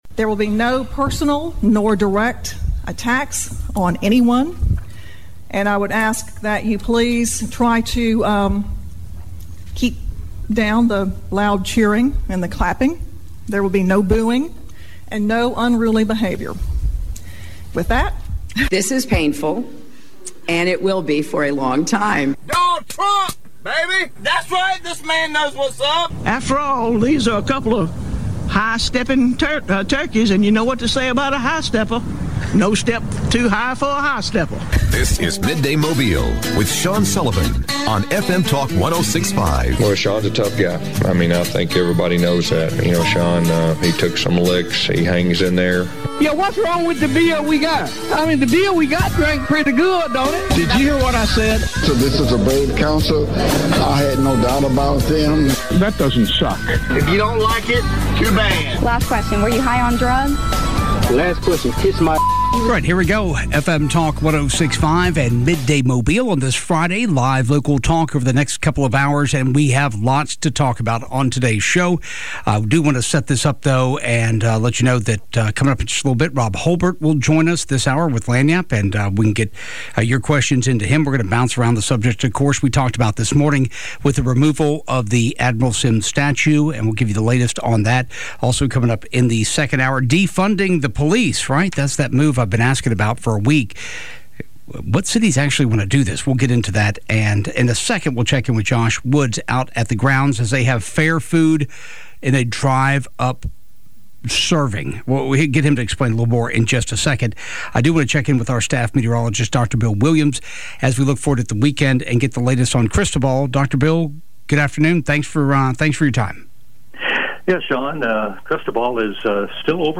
talks with listeners about the removal of the Admiral Semmes statue in Mobile